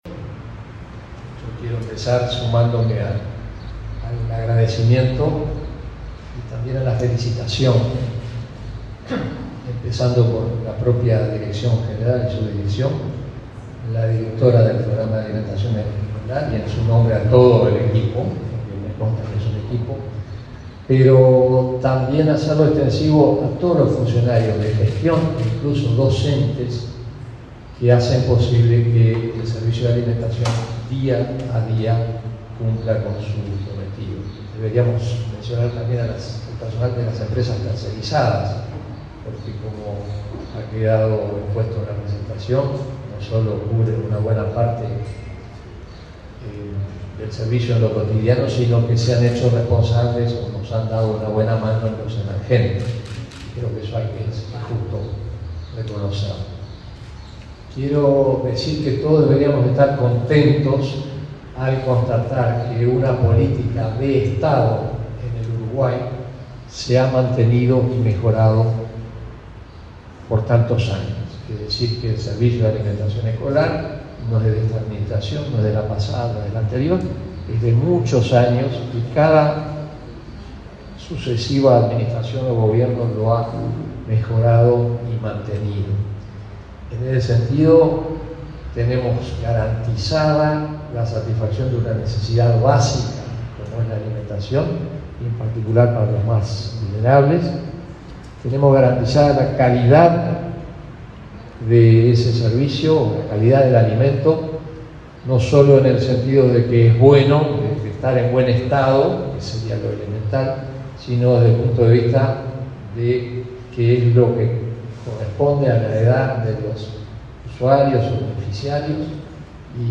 Palabras de autoridades de la ANEP
Palabras de autoridades de la ANEP 18/12/2023 Compartir Facebook X Copiar enlace WhatsApp LinkedIn El consejero de la Administración Nacional de Educación Pública (ANEP) Juan Gabito Zóboli y la directora general de Educación Inicial y Primaria, Olga de las Heras, participaron en el acto de rendición de cuentas del Programa de Alimentación Escolar (PAE) 2023.